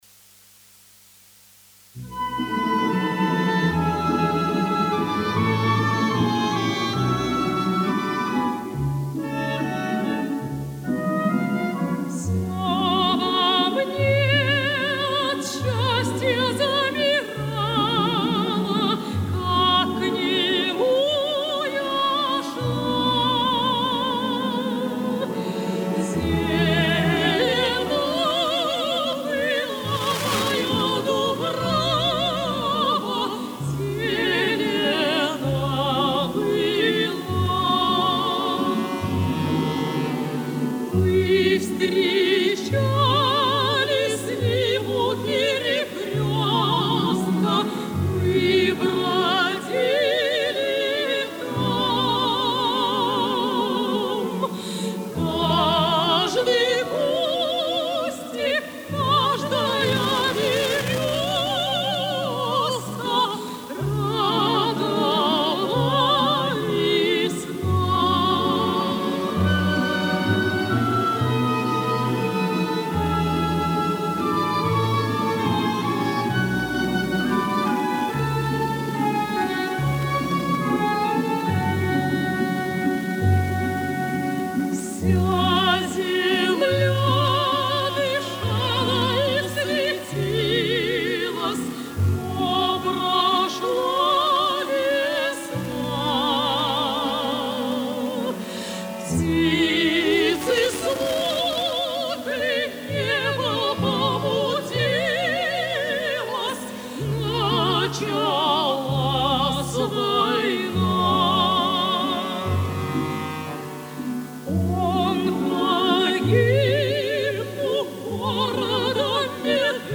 Проникновенно поют сёстры...